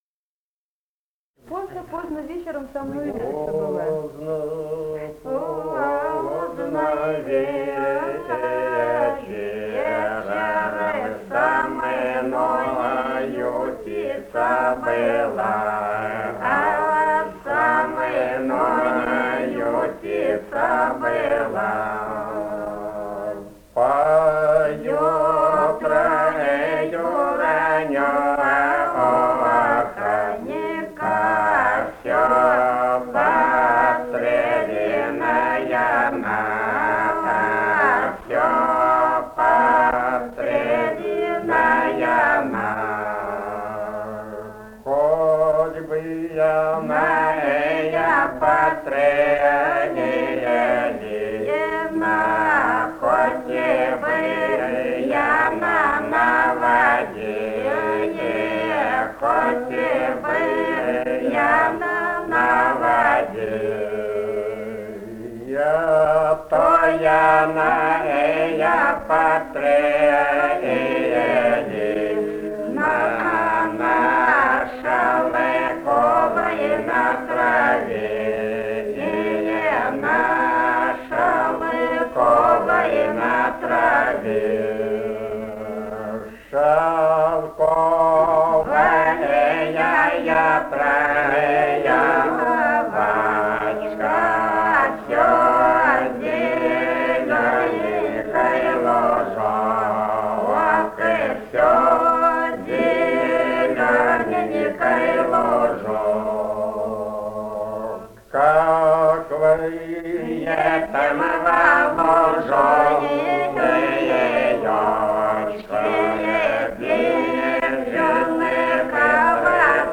Этномузыкологические исследования и полевые материалы
Грузия, г. Тбилиси, 1971 г.